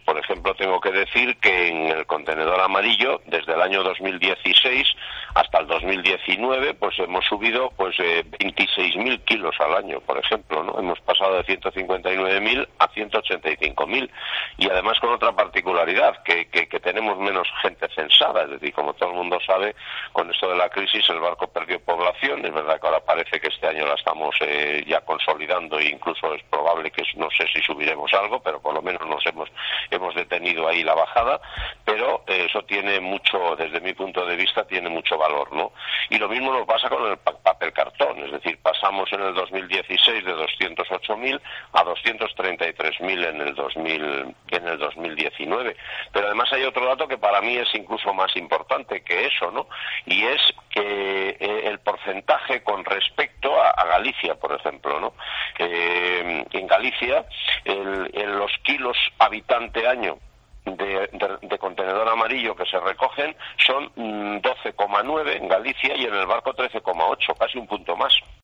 Declaraciones del alcalde de O Barco sobre el reciclaje